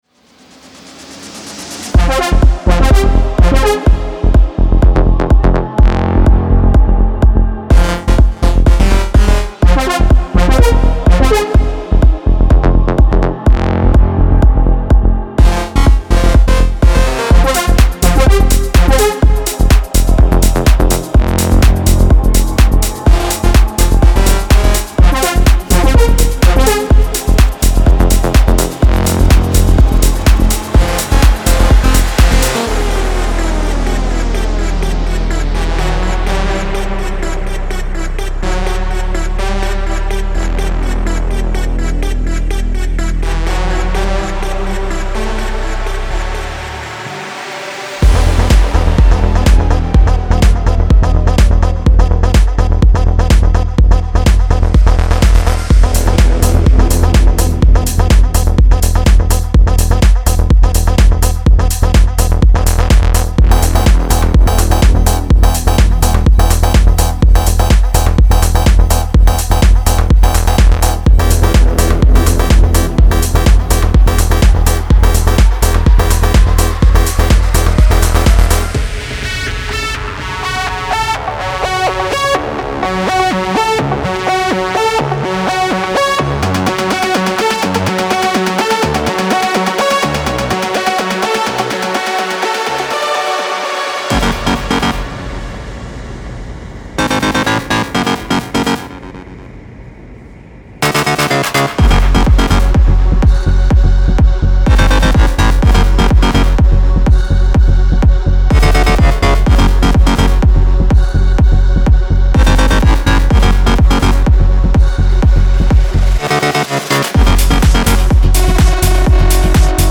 Genre:Melodic Techno
デモサウンドはコチラ↓
40 Vocal Loops